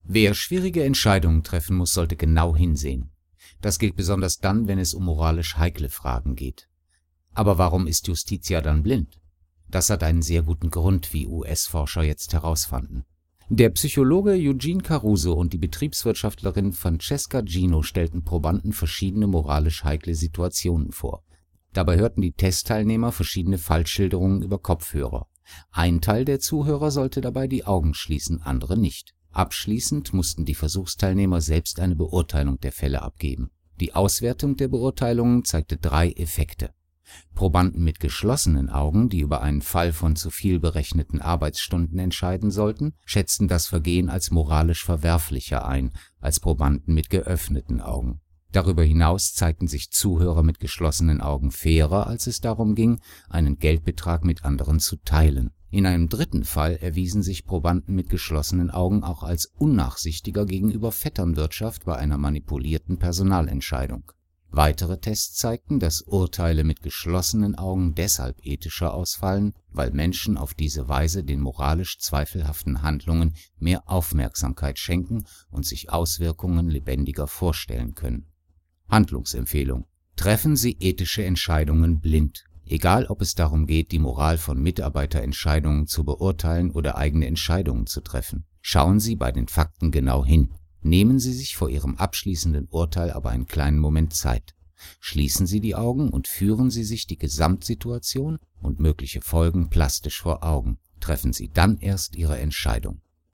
Sprecher _Voice Actor
Sie suchen nach einer sympathischen, tragfähigen Stimme....
Mikrofon: Rode NT-2A
Sachtext